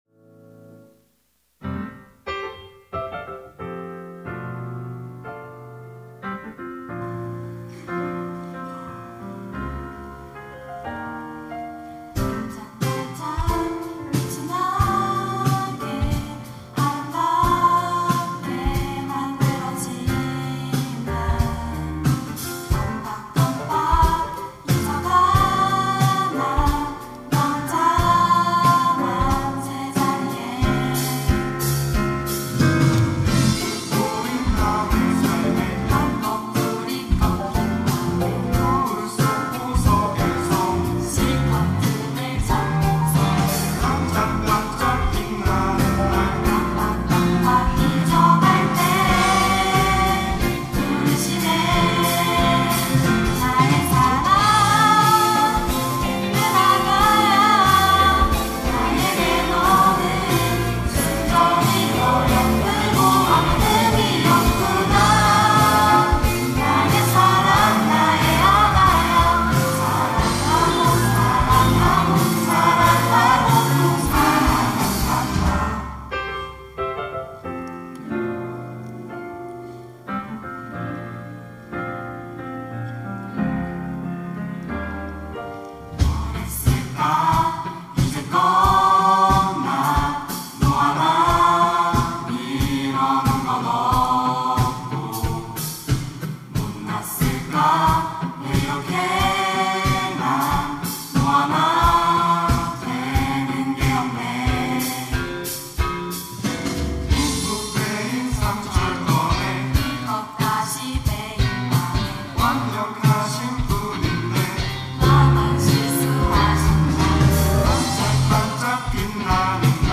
특송과 특주 - S.O.S (Song of Song)